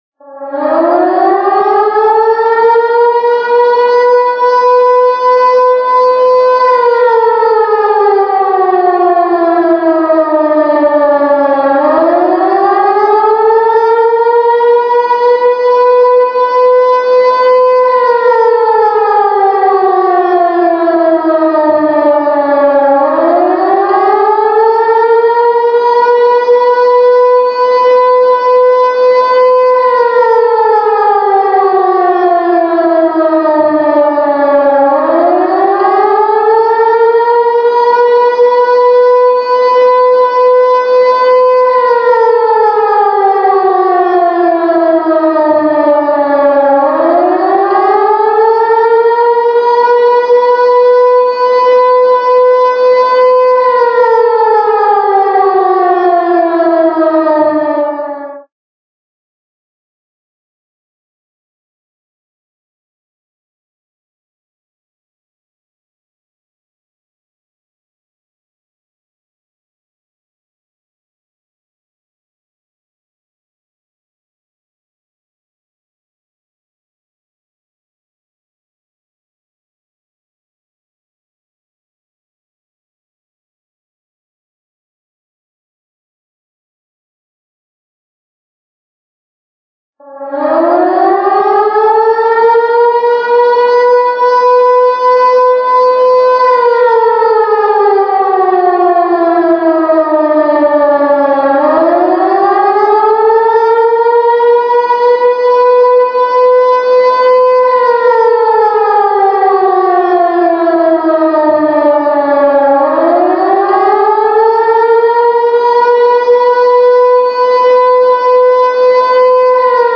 kõlavad ohusireenid
Sireeni heli koosneb üheminutilisest tõusva ja langeva tooniga signaalist, mida korratakse vähemalt kolm korda 30-sekundiliste pausidega. Kokku kestab sireenide heli umbes 4 minutit.
Sireeni-heli.wav